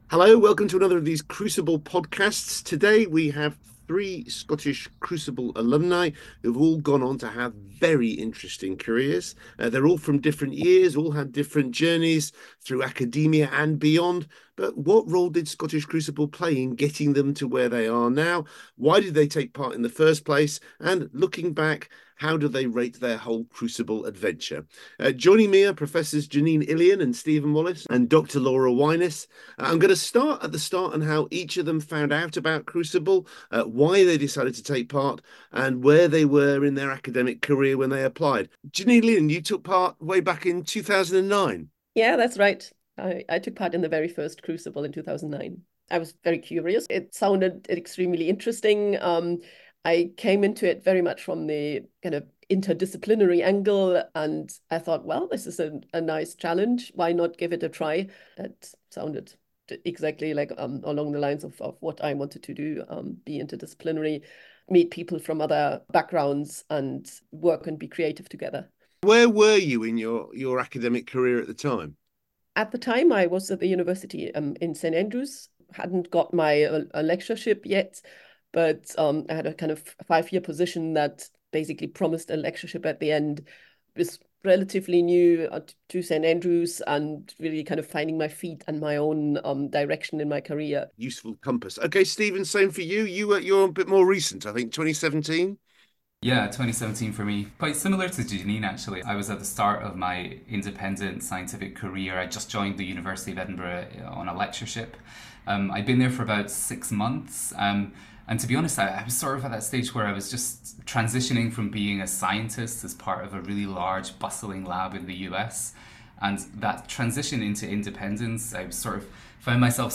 In this podcast, we hear the Crucible reflections of 3 more Scottish Crucible Alumni in conversation with Quentin Cooper.